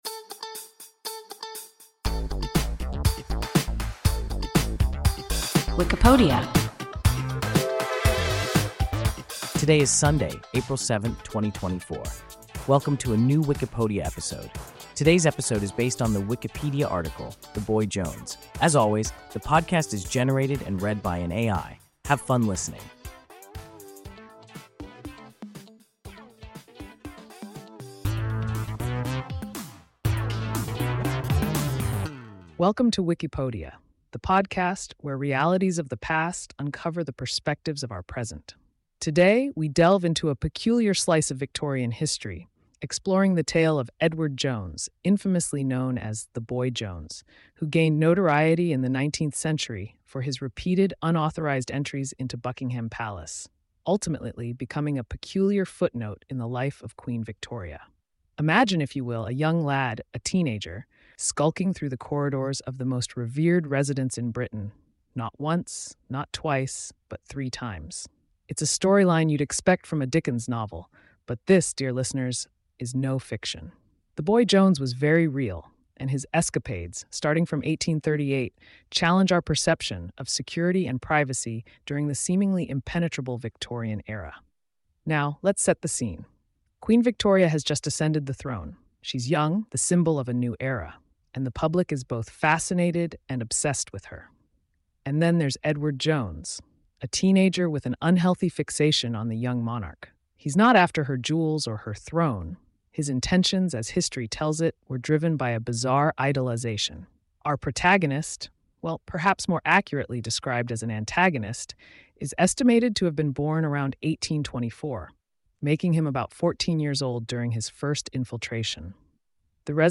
The boy Jones – WIKIPODIA – ein KI Podcast